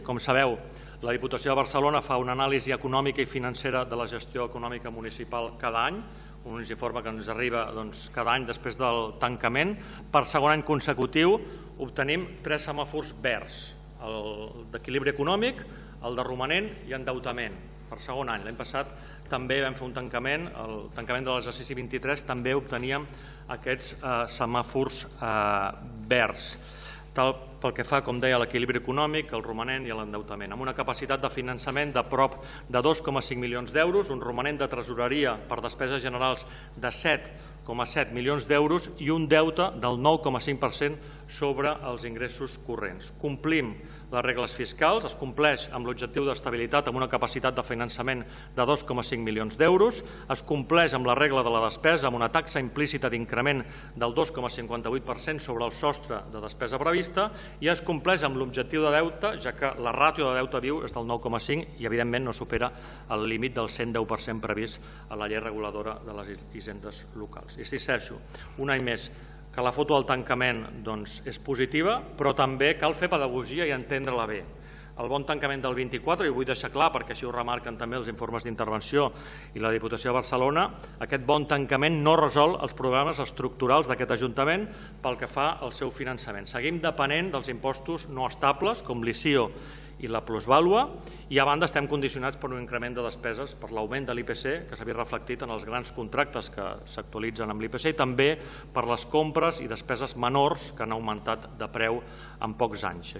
El ple d’aquest dimarts 1 de juliol ha aprovat el destí del superàvit del 2024.
Tot i això, va advertir de la dependència dels ingressos volàtils:
alcalde-3-semafors-verds1.mp3